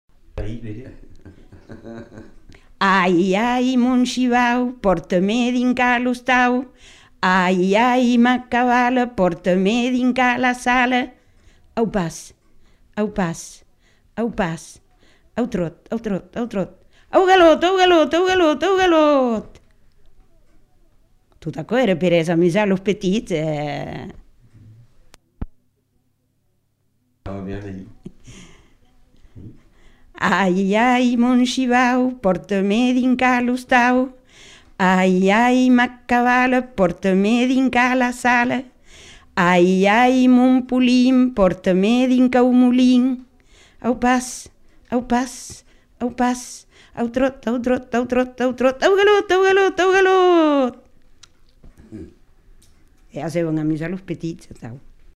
Aire culturelle : Marmandais gascon
Lieu : Tonneins
Genre : forme brève
Type de voix : voix de femme
Production du son : chanté
Classification : formulette enfantine
Notes consultables : Formulette dite deux fois.